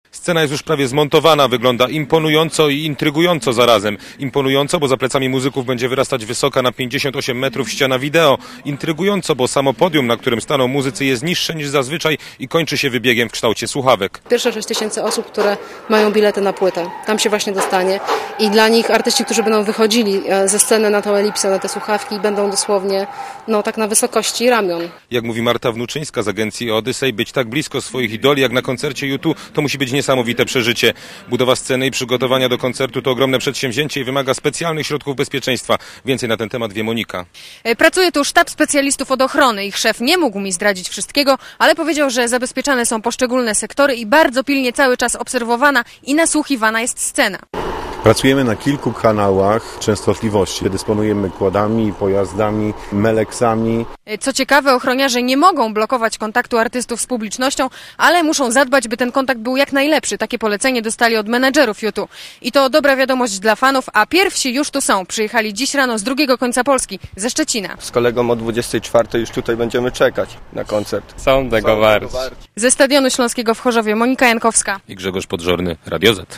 Na miejscu są reporterzy Radia ZET.
Relacja